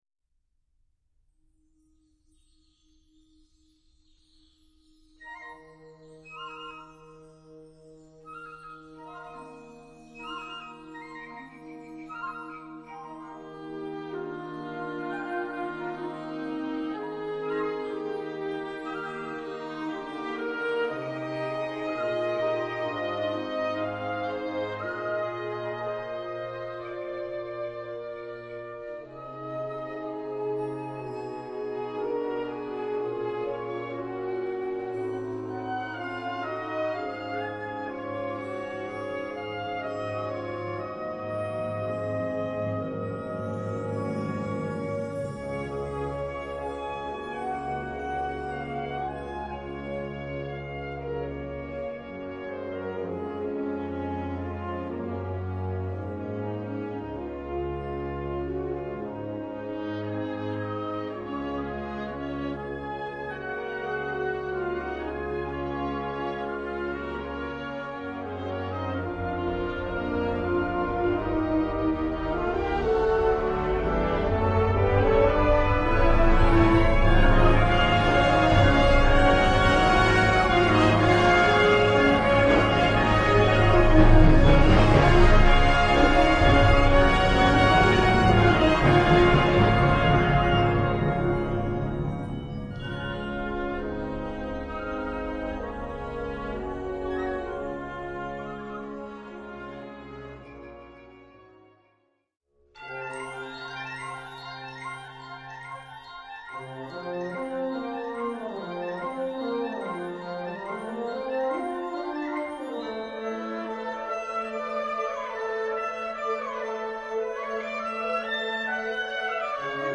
Gattung: Konzertante Blasmusik
A4 Besetzung: Blasorchester Zu hören auf